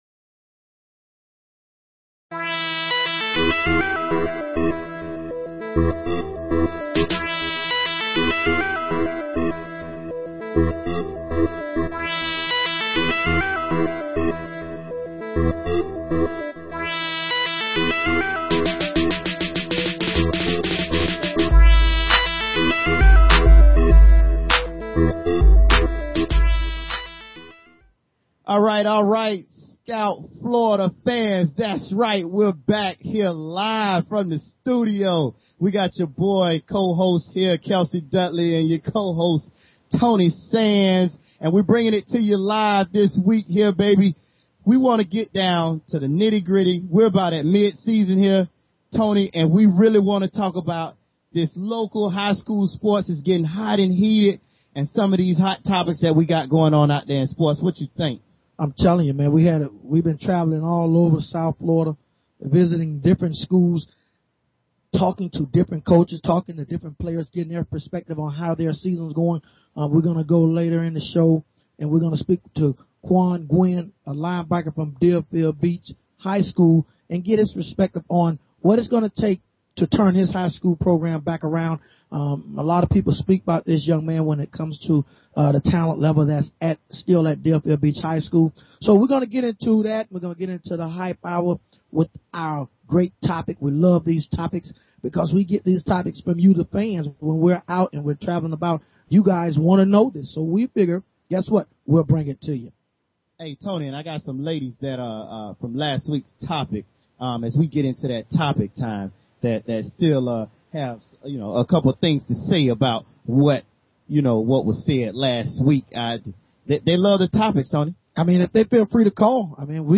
Talk Show Episode, Audio Podcast, Scout_Florida and Courtesy of BBS Radio on , show guests , about , categorized as